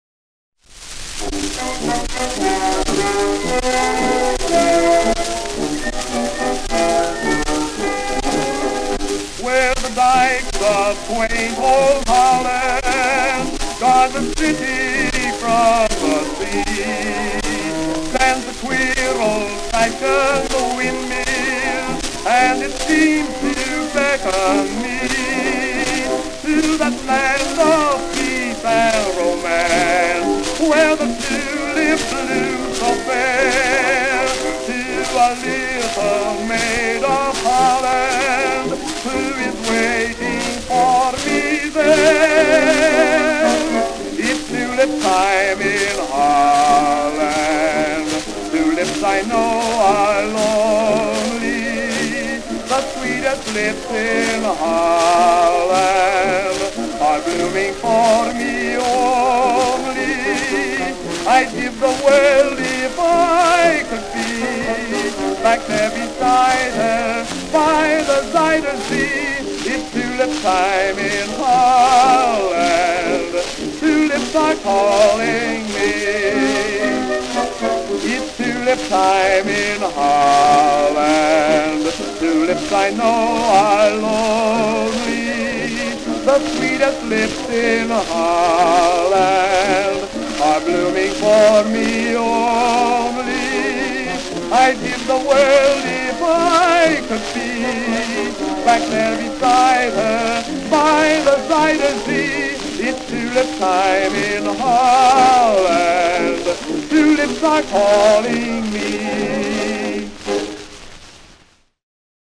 Tenor Solo